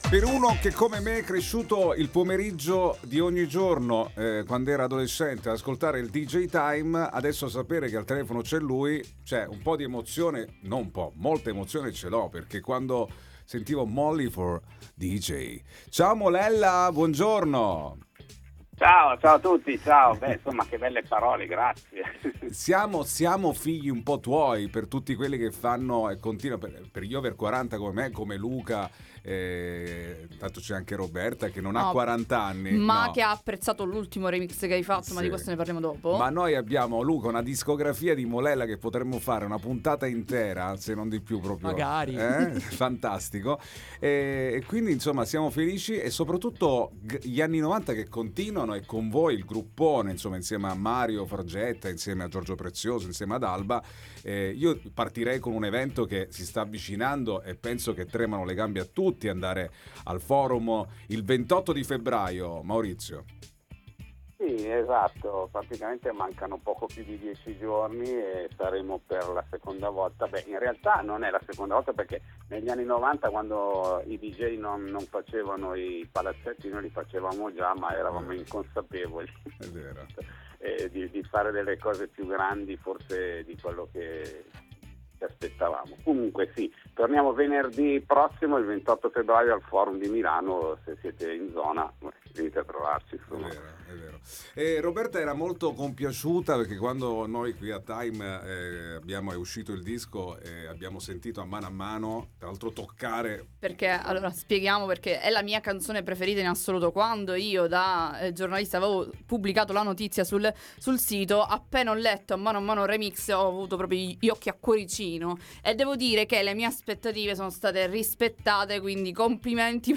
All Inclusive intervista Maurizio Molella